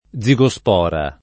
[ +z i g o S p 0 ra ]